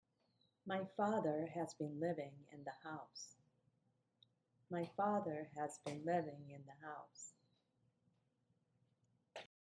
内容語は強く、高く、長く、
機能語は軽く、素早く発音されるといことです。
軽く、ほぼ繋がって聞こえたのではないでしょうか？